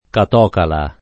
catocala [ kat 0 kala ]